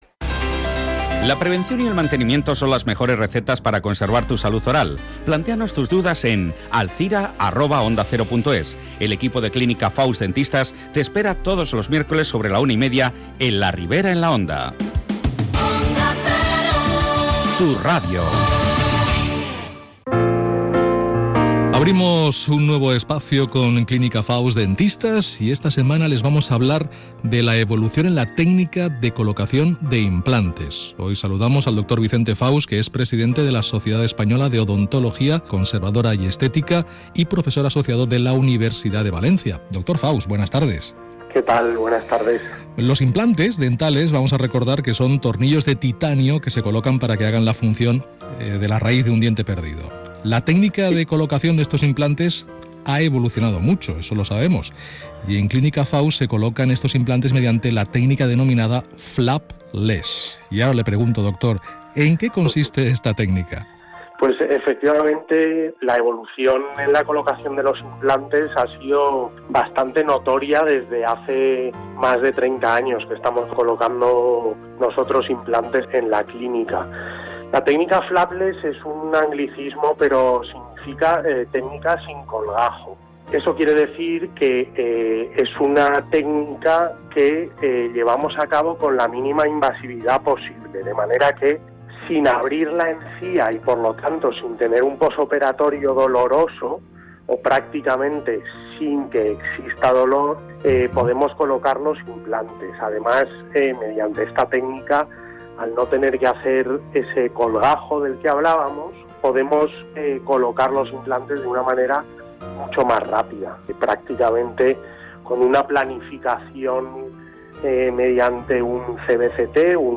Entrevistas Onda Cero Alzira